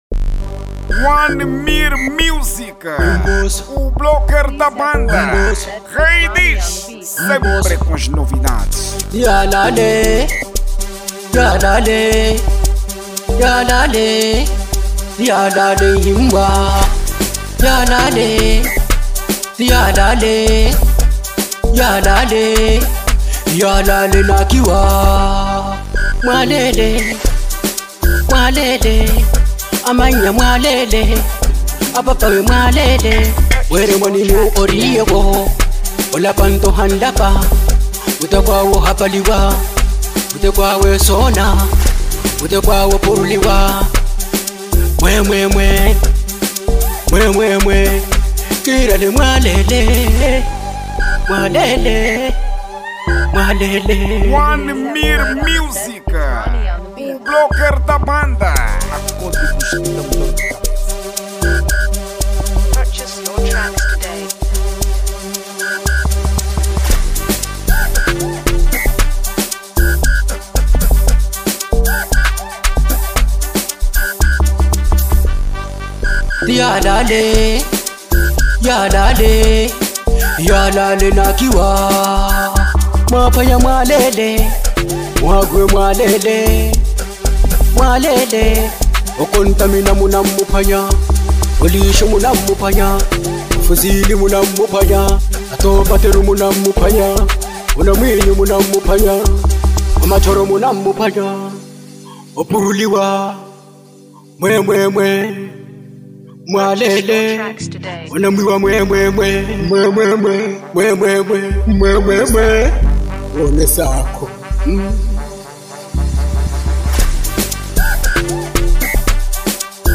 Genereo : MAPIANO